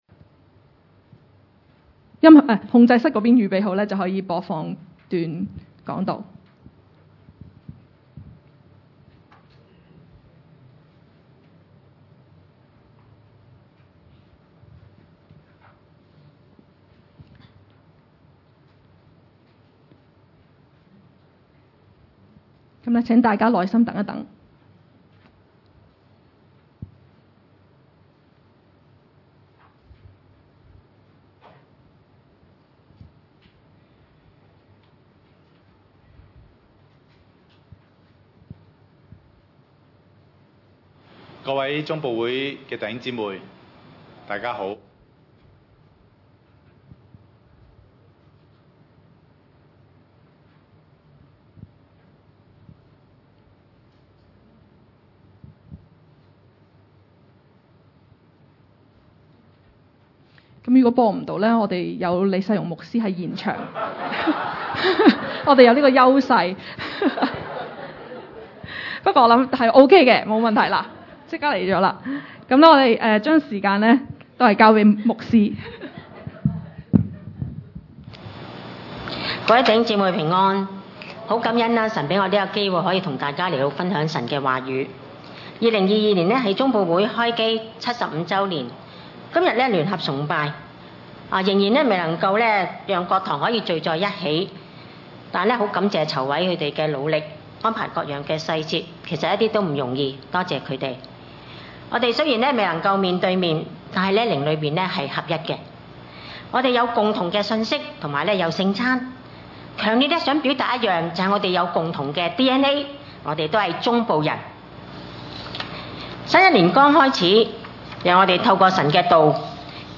以弗所書 1:9-10、4:1-16 崇拜類別: 主日午堂崇拜 以弗所書 1:9-10 9都是照祂自己所預定的美意，叫我們知道祂旨意的奧祕， 10要照所安排的，在日期滿足的時候，使天上、地上、一切所有的都在基督裡面同歸於一。